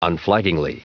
Prononciation du mot unflaggingly en anglais (fichier audio)